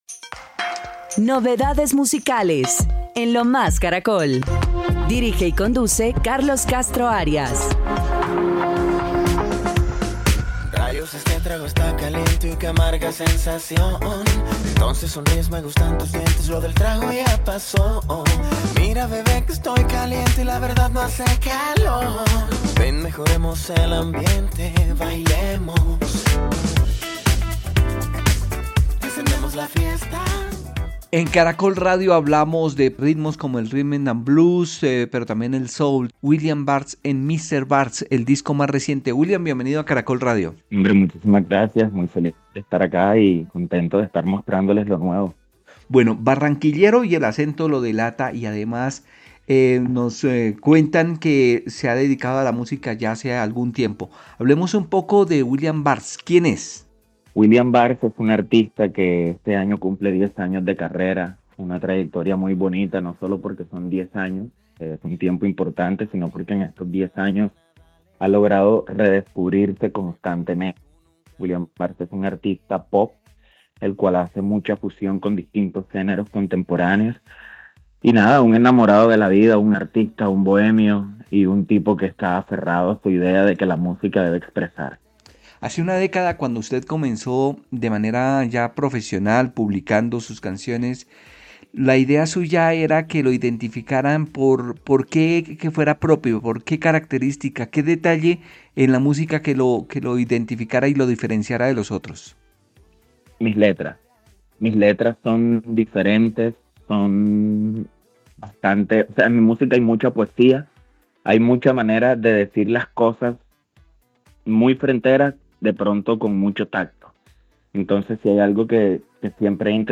En diálogo con Lo Más Caracol